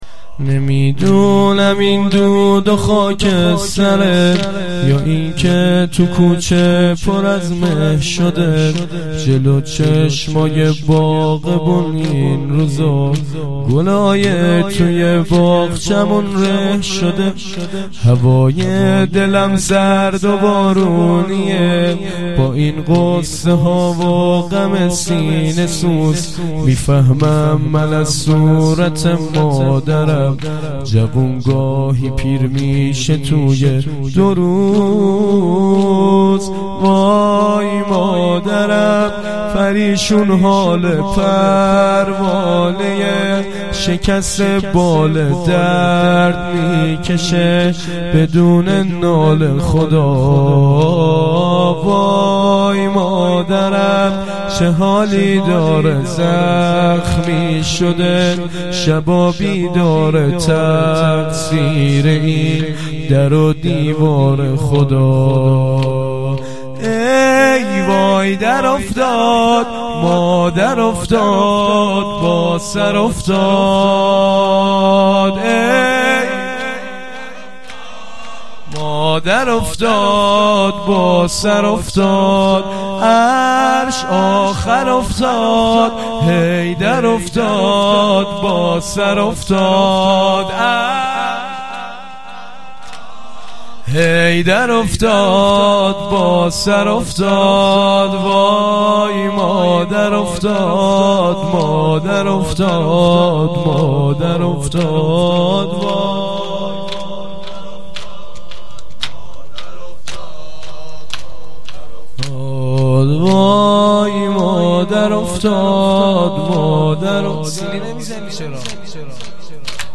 هیأت عاشقان ثاراللّه
مراسم هفتگی ۳۰ آبان هییت عاشقان ثارالله اشتراک برای ارسال نظر وارد شوید و یا ثبت نام کنید .